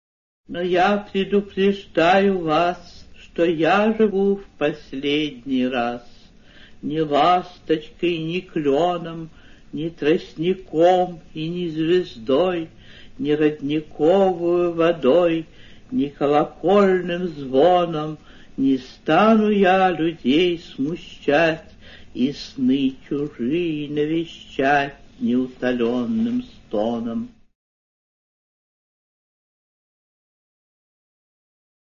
3. «А.А.Ахматова (читает автор) – “Но я предупреждаю вас…”» /